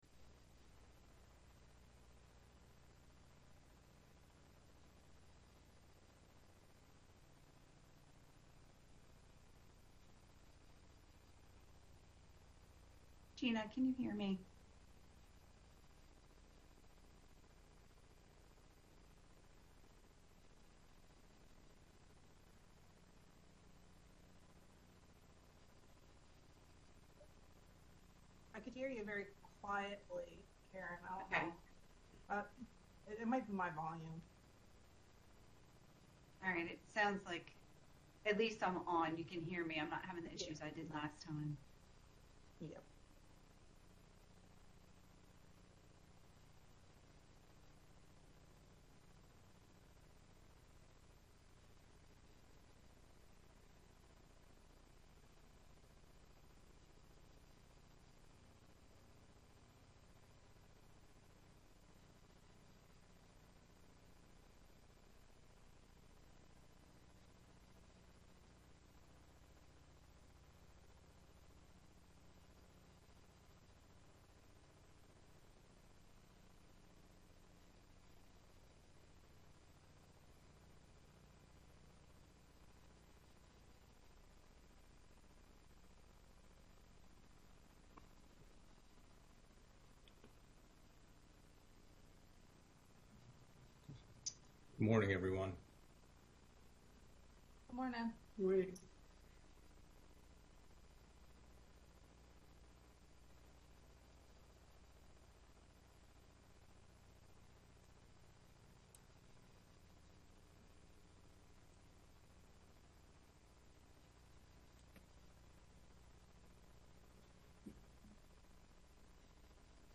Pension Fund Committee Meeting | Sussex County